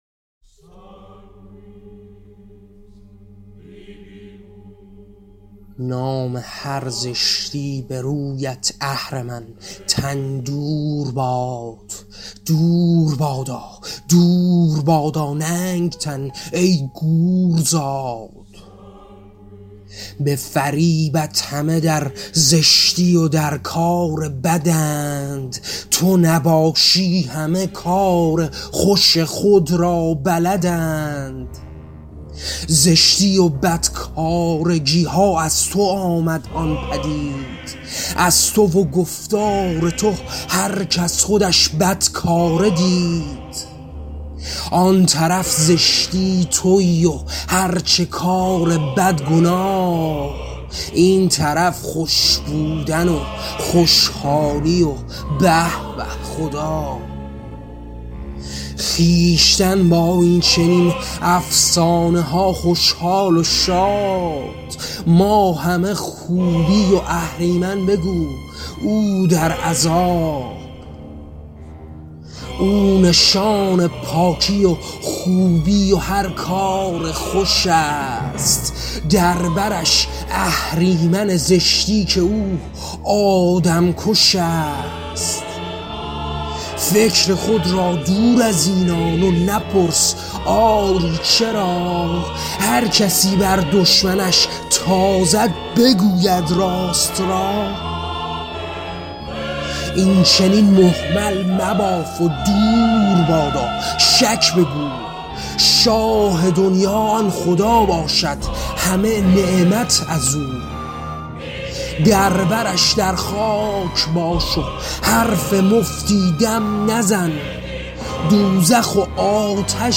کتاب قیام؛ شعرهای صوتی؛ مهمل: نقد فرافکنیِ زشتی‌ها و افسانه‌ی اهرمن